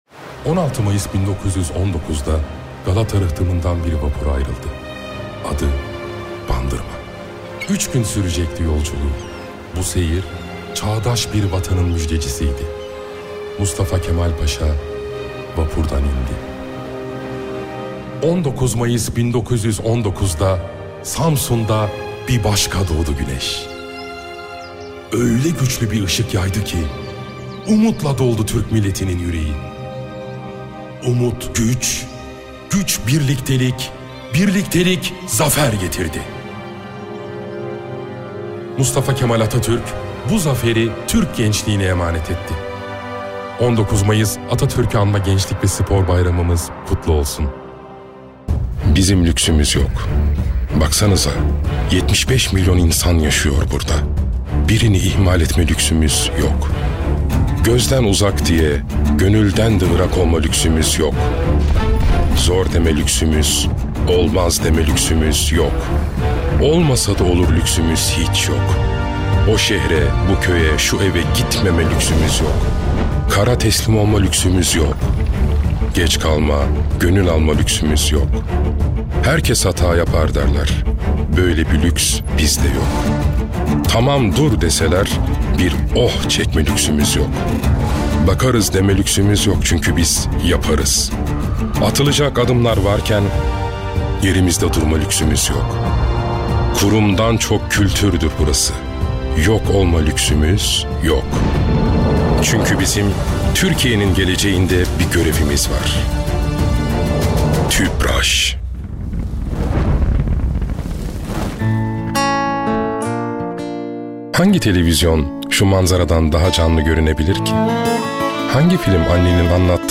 Erkek
Volvo - Karşı Konulmaz Teklif Karakter Eğlenceli, Fragman, Güvenilir, Karakter, Animasyon, Karizmatik, Dialekt, Sıcakkanlı, Tok / Kalın, Dış Ses, Dostane,